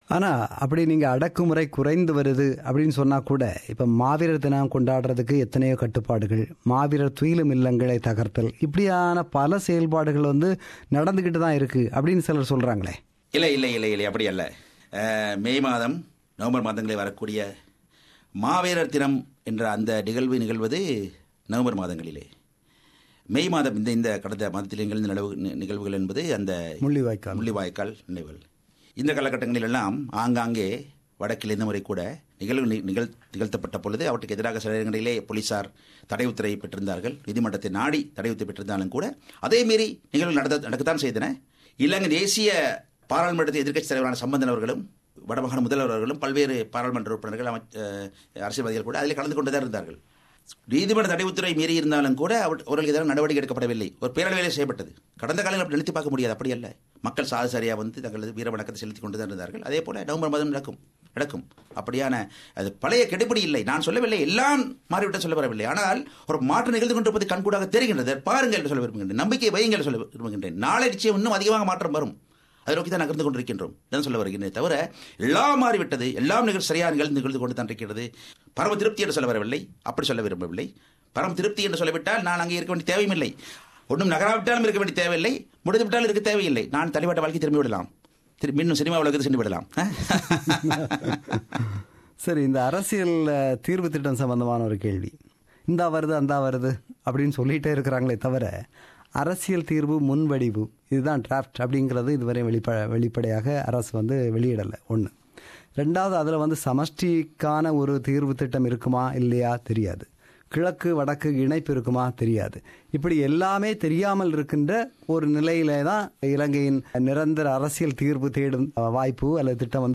Interview with Minister Mano Ganesan – Part 2